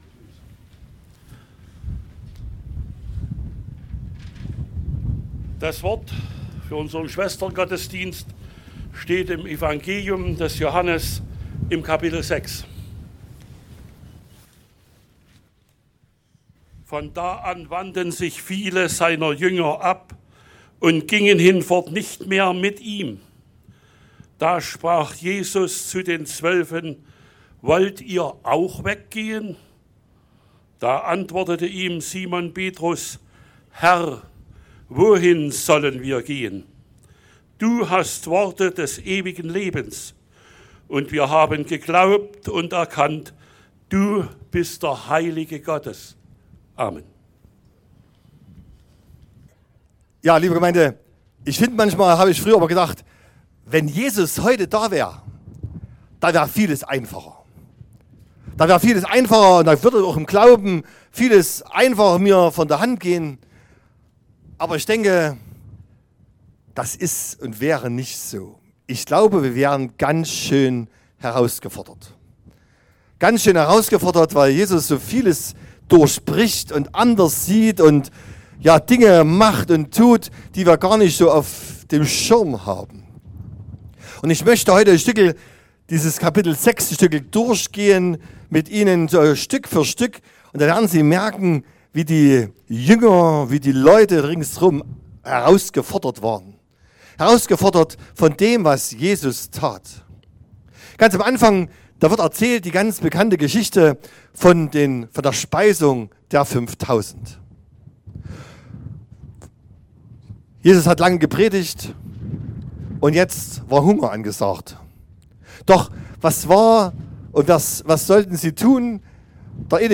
28.04.2024 – gemeinsamer Schwesterngottesdienst
Predigt und Aufzeichnungen